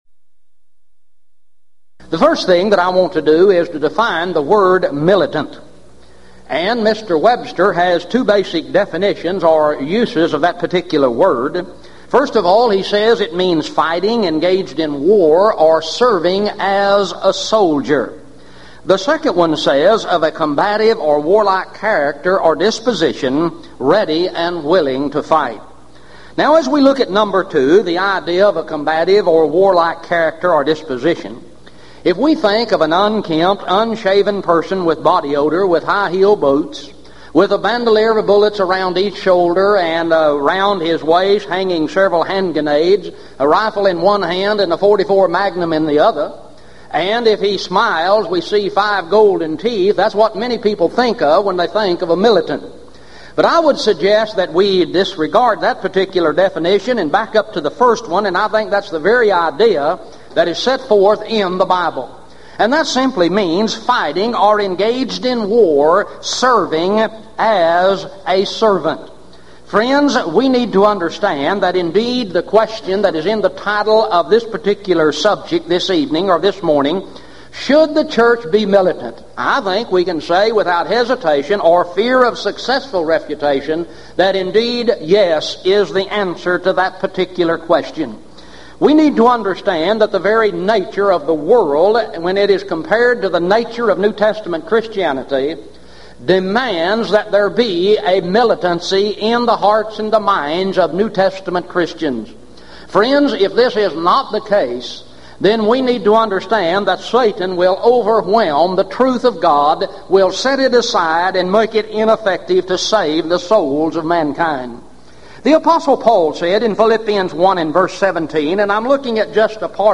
Event: 1996 Gulf Coast Lectures
lecture